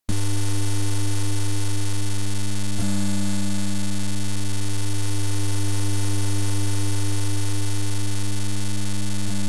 And you have a weird "slow whirlwind" stereo effect created by the background sound.